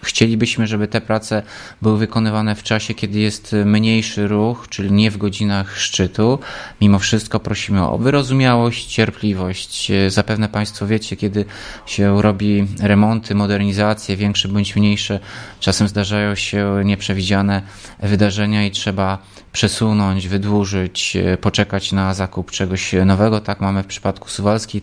– Kolejnym etapem będzie wyłożenie zewnętrznej warstwy asfaltu – mówi Tomasz Andrukiewicz, prezydent Ełku.